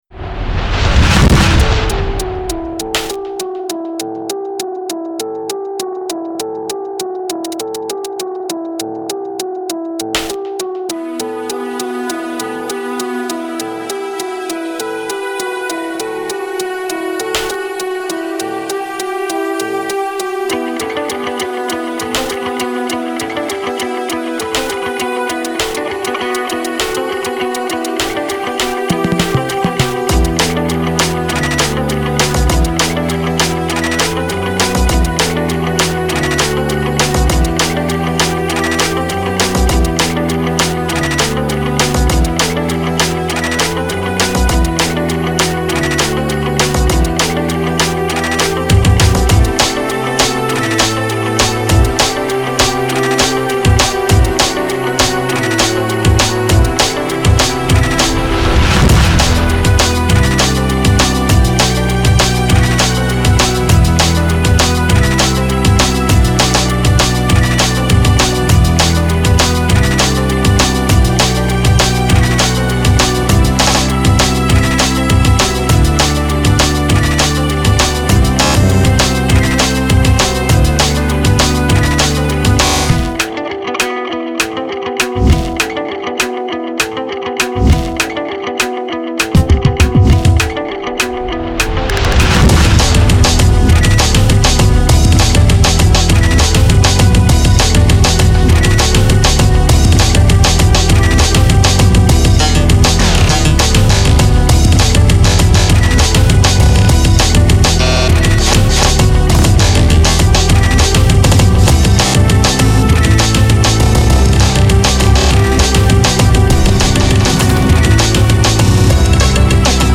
Genre : Rock, Alternative Rock